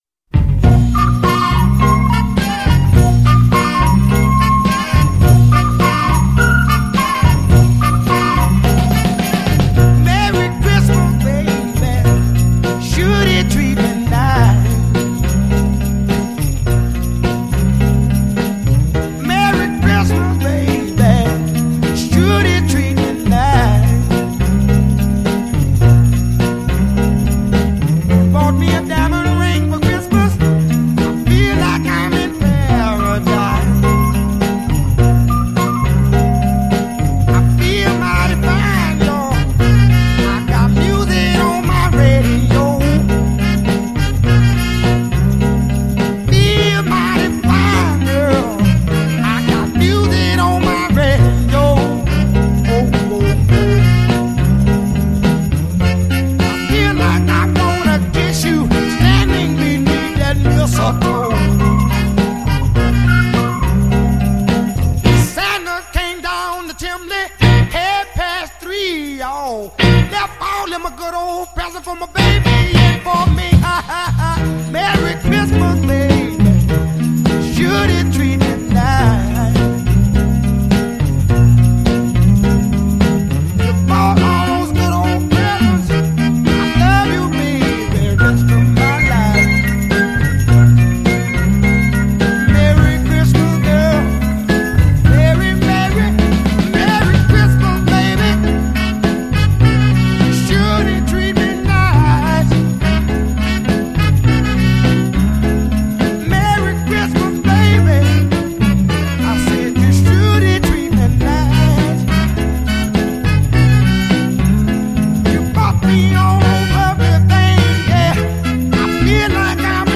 Sticking with the 60’s Soul festivities
Rhythm & Blues Christmas classic